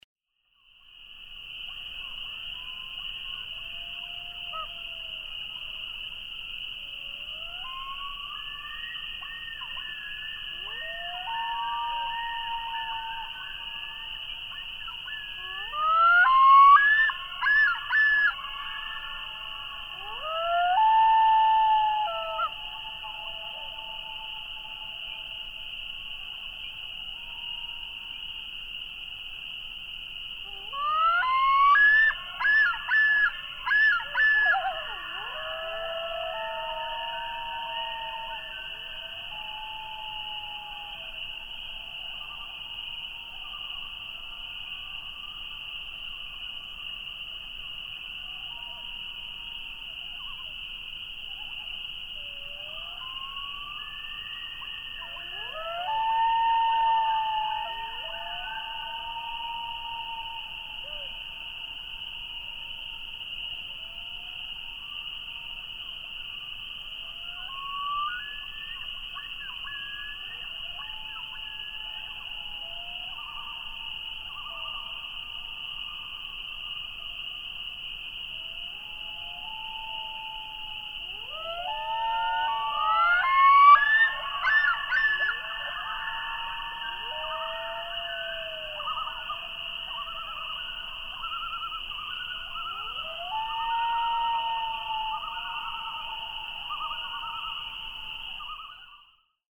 Common loon
Listen to the soft "hoot" of a nearby bird at 0:05, followed by wails (e.g., 0:10, 0:20) and male yodels (e.g., nearby at 0:16, 0:30).
Lake Opeongo in Algonquin Provincial Park, Ontario, Canada.
672_Common_Loon.mp3